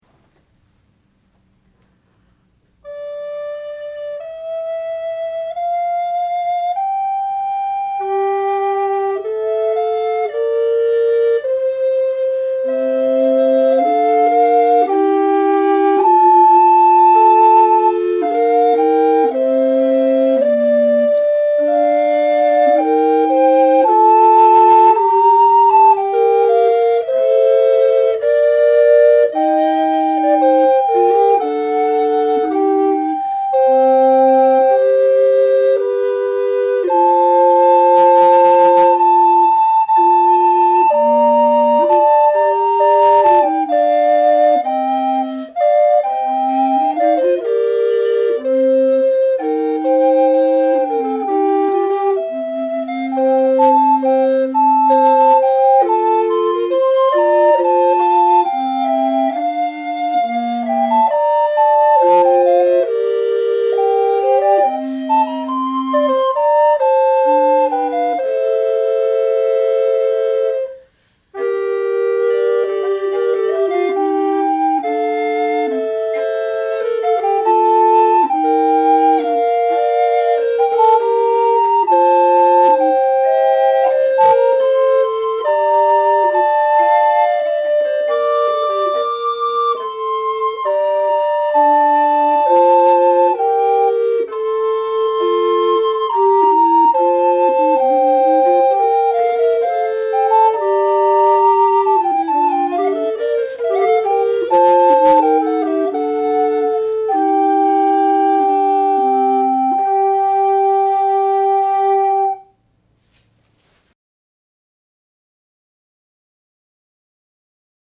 From the 5 November 2006 concert, La Caccia